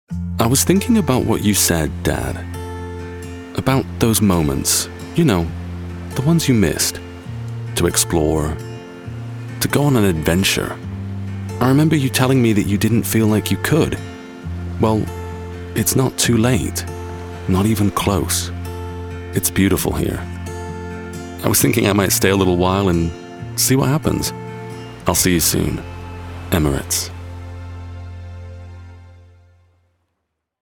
Young Adult, Adult, Mature Adult
His voice is warm, assured, friendly, and authentic.
international english
COMMERCIAL 💸